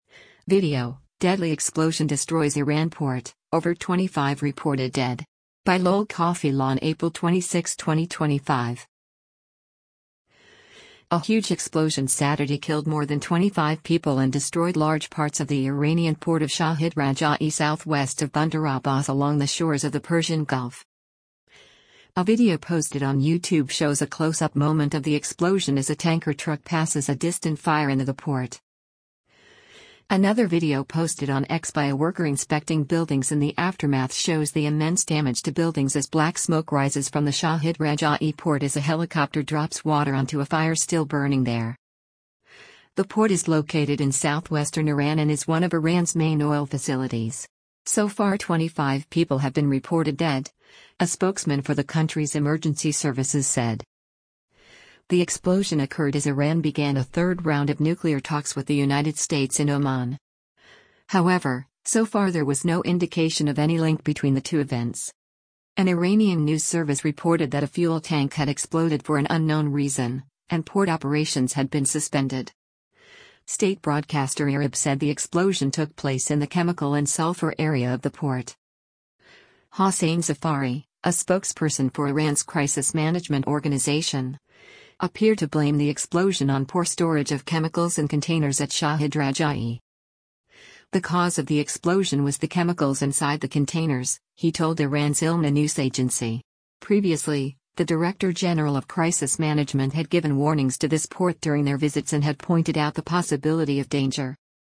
A video posted on YouTube shows a close up moment of the explosion as a tanker truck passes a distant fire in the the port: